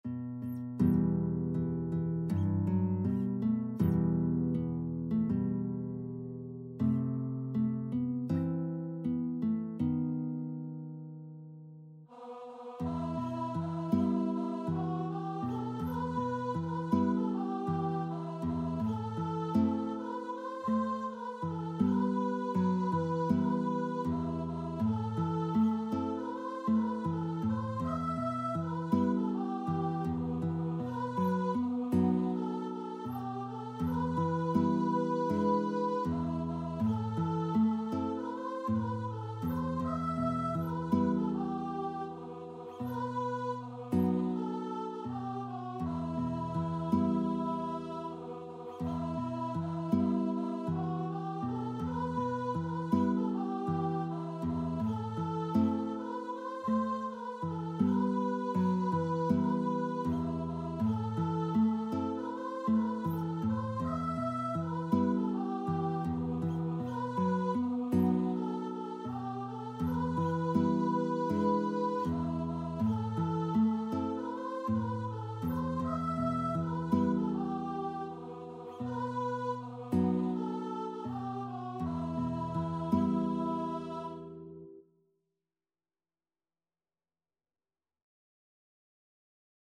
Free Sheet music for Guitar and Vocal
4/4 (View more 4/4 Music)
E minor (Sounding Pitch) (View more E minor Music for Guitar and Vocal )
Andante espressivo
Traditional (View more Traditional Guitar and Vocal Music)
world (View more world Guitar and Vocal Music)